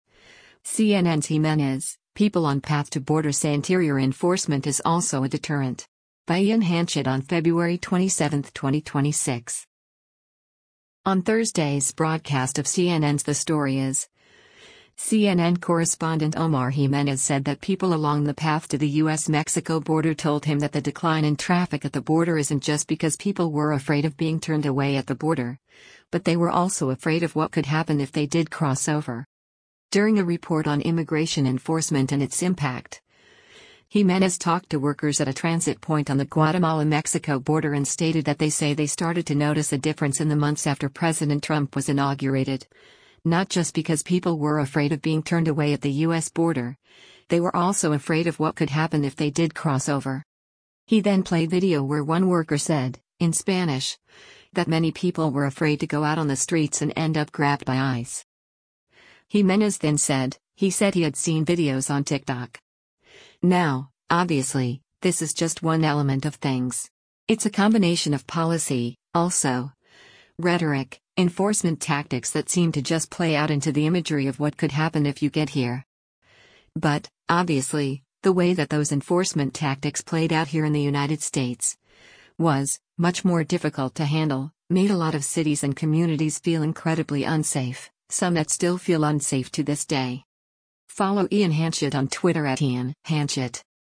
During a report on immigration enforcement and its impact, Jimenez talked to workers at a transit point on the Guatemala-Mexico border and stated that “They say they started to notice a difference in the months after President Trump was inaugurated, not just because people were afraid of being turned away at the U.S. border, they were also afraid of what could happen if they did cross over.”
He then played video where one worker said, in Spanish, that many people were afraid to go out on the streets and end up grabbed by ICE.